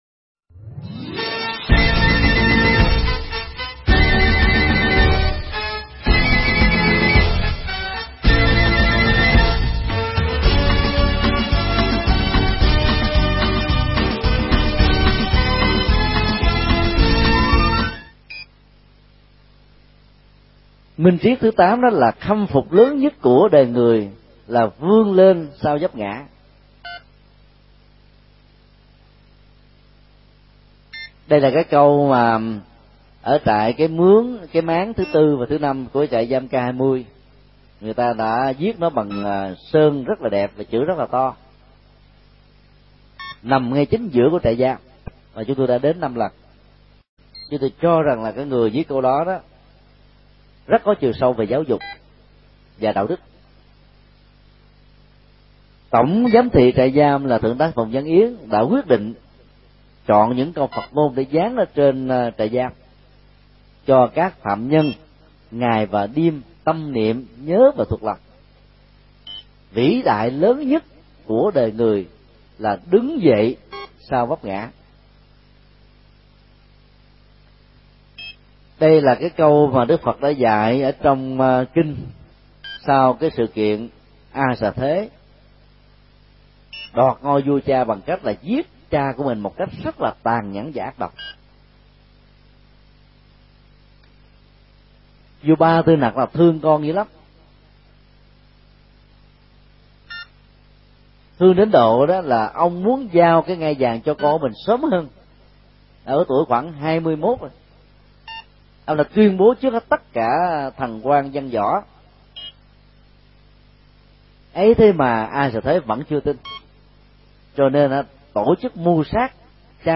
Mp3 pháp thoại Mười bốn điều Phật dạy 2B – điều 5-8: Đánh mất mình
giảng tại Chùa Xá Lợi